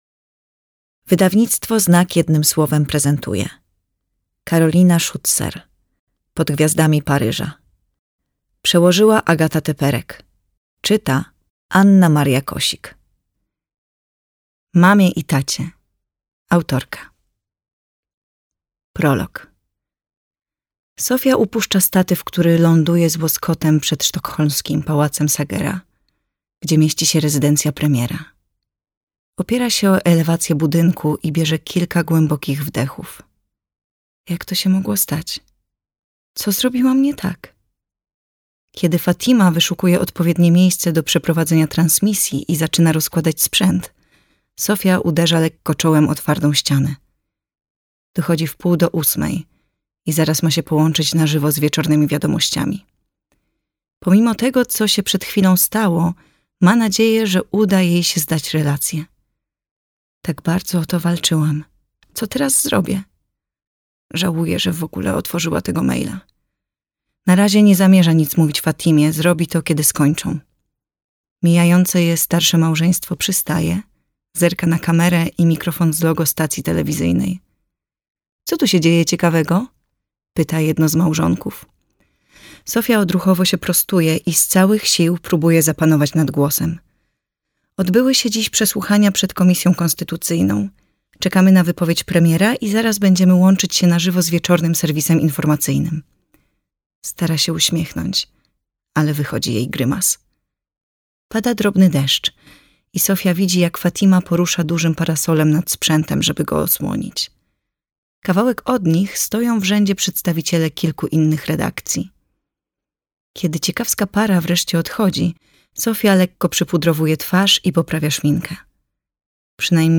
Pod gwiazdami Paryża - Schützer Karolina - audiobook